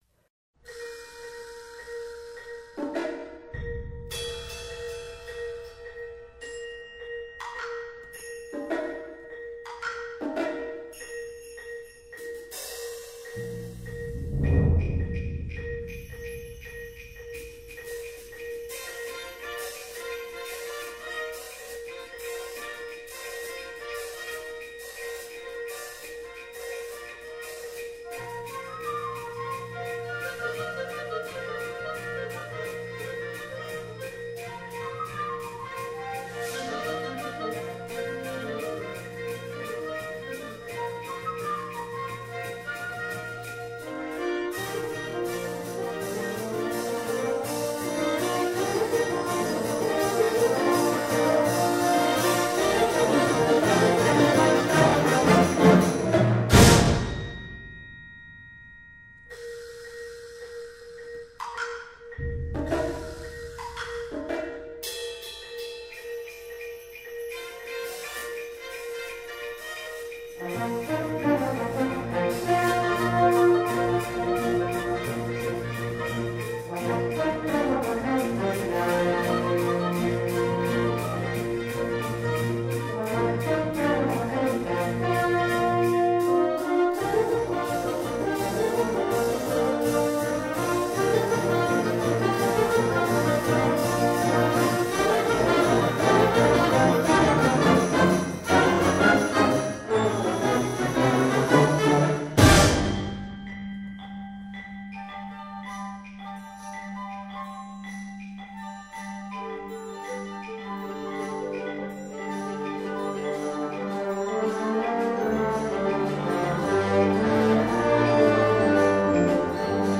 wildly energetic and rhythmic piece for concert band